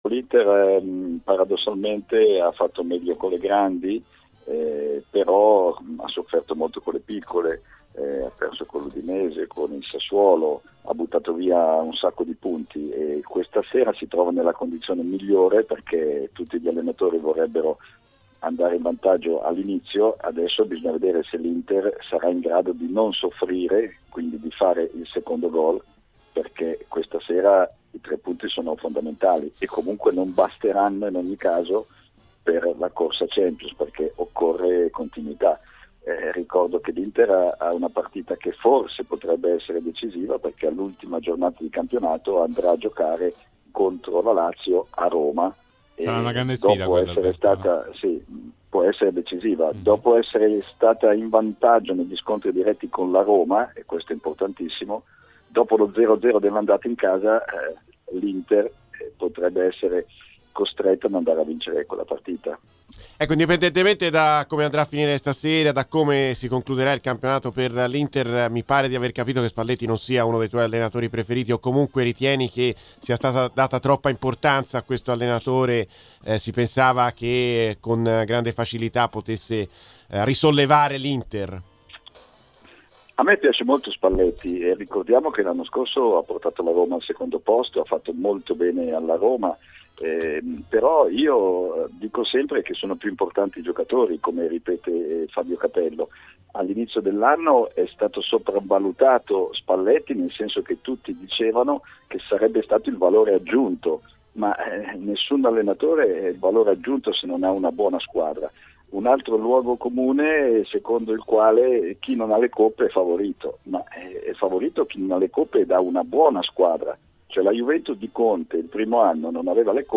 nel suo intervento all'interno del Live Show ha commentato così i primi minuti di gioco della partita tra Inter e Cagliari